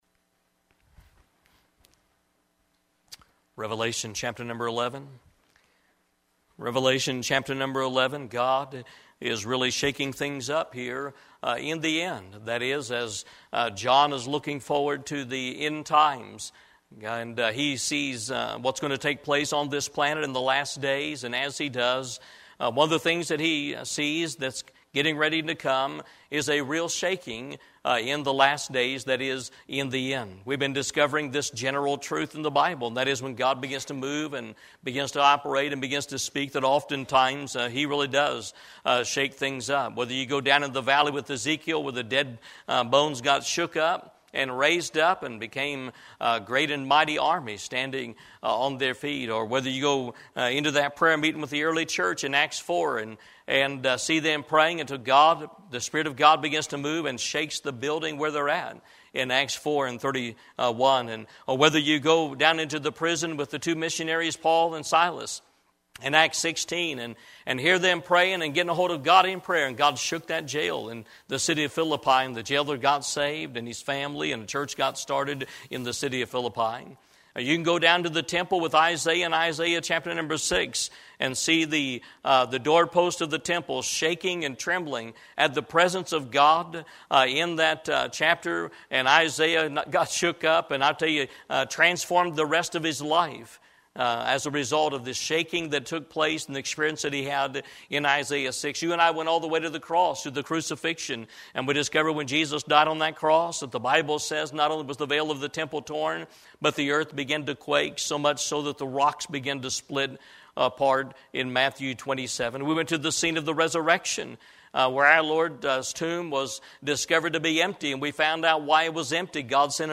Eastern Gate Baptist Church - Shaking It Up 22